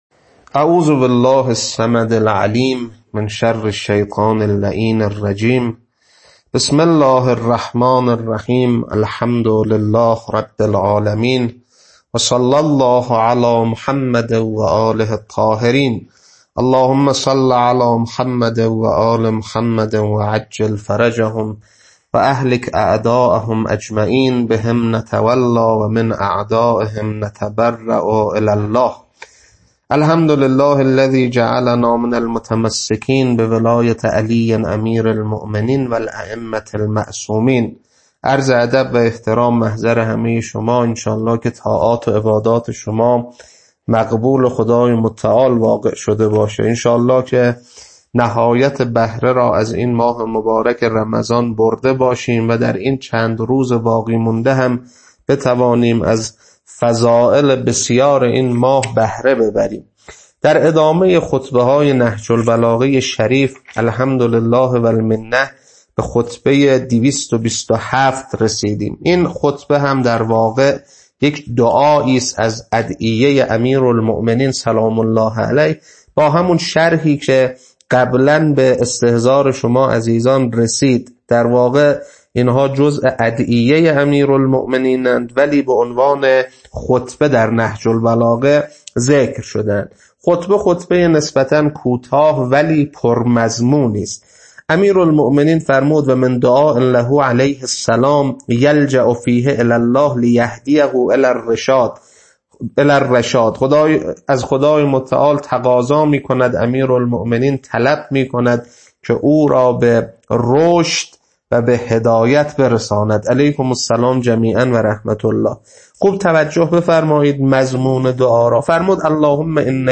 خطبه-227.mp3